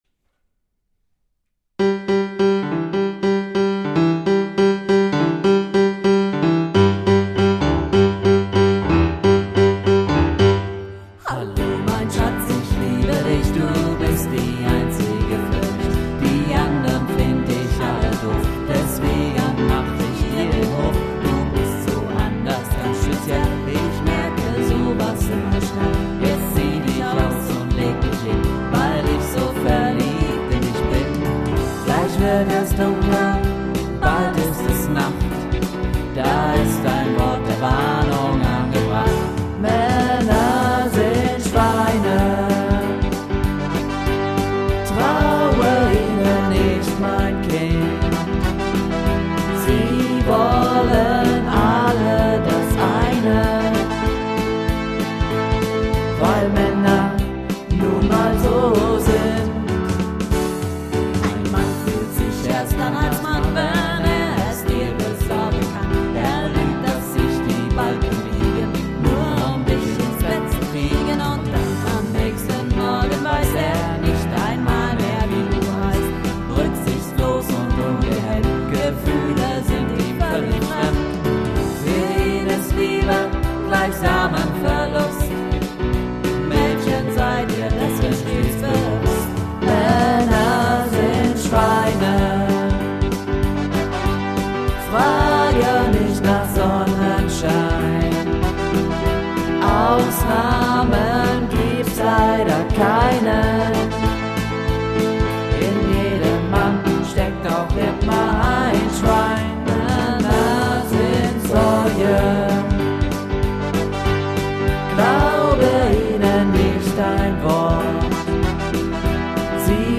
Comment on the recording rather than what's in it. Hier findet ihr ein paar Aufnahmen von unseren Bandproben....